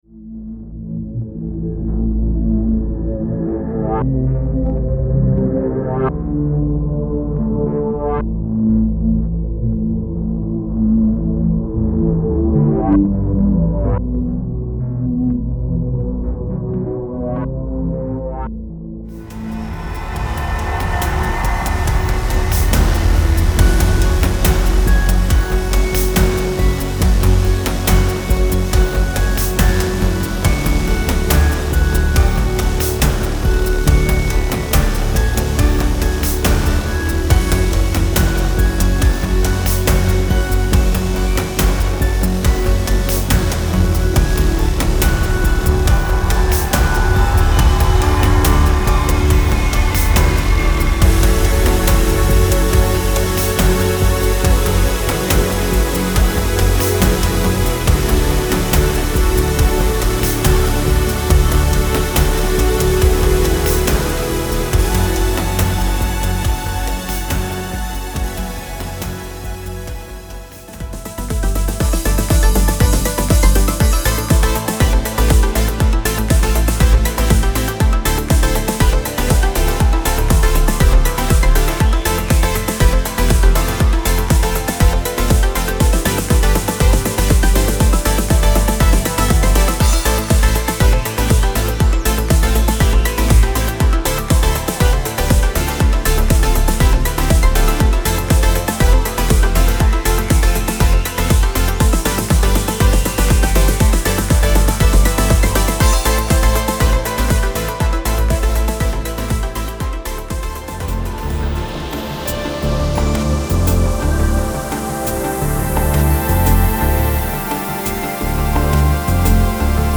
Melancholy and euphoria are very close.
There is also a lot of bottom end in the music.